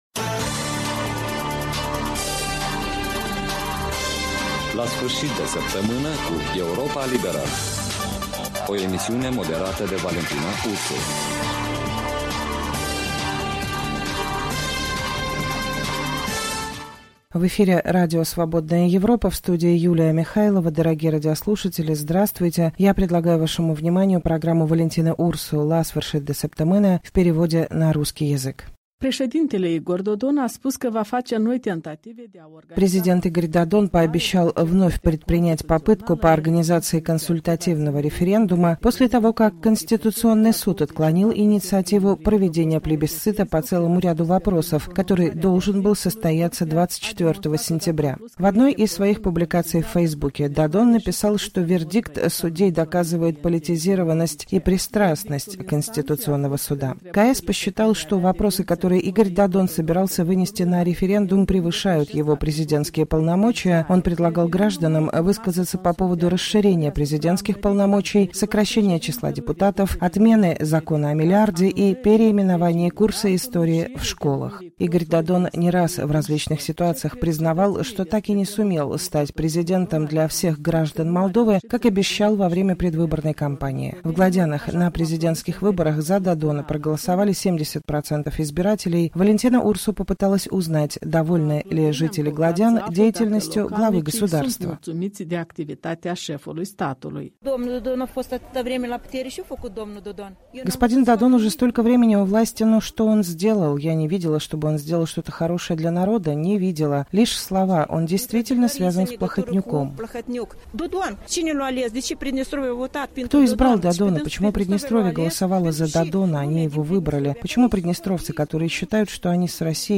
беседует с жителями Глодян